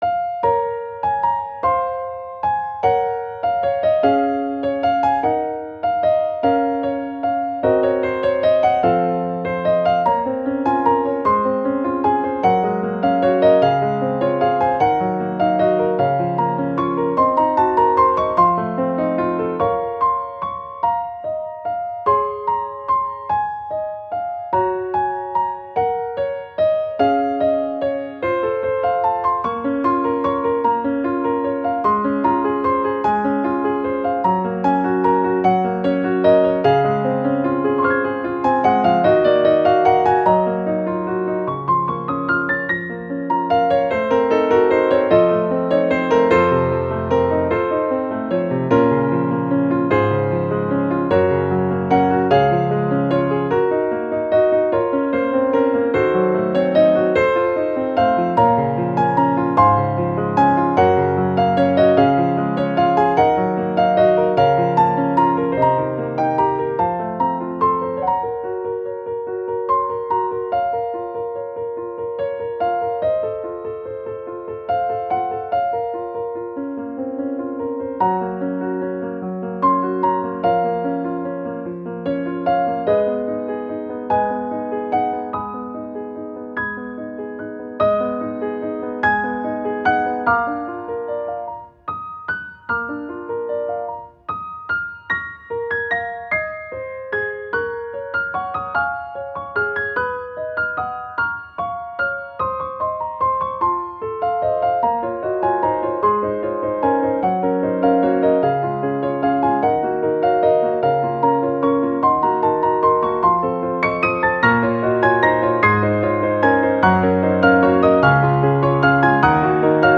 -oggをループ化-   悲しい 情熱 2:14 mp3